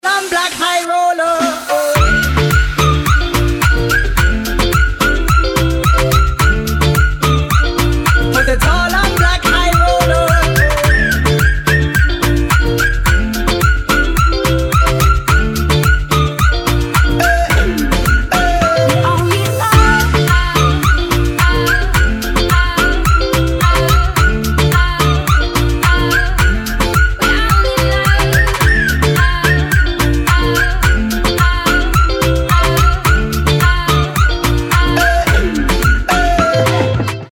• Качество: 320, Stereo
dance
EDM
tropical house